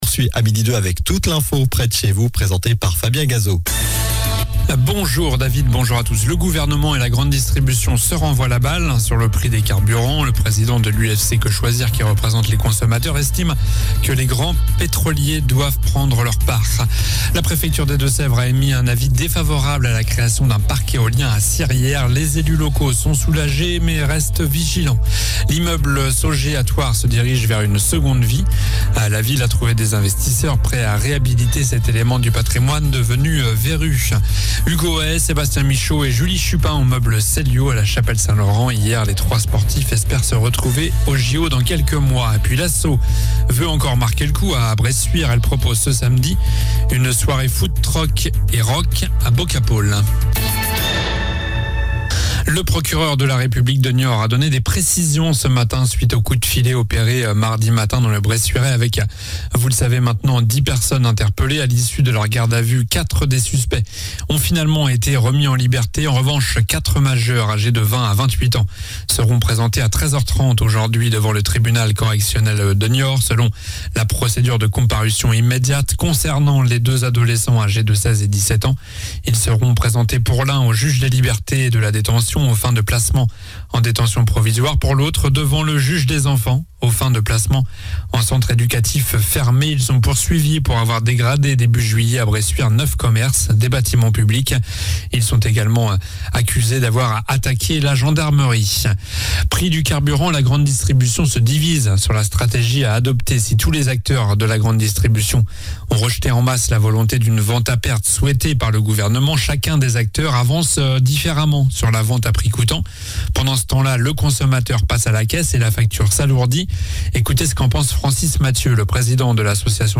Journal du jeudi 28 septembre (midi)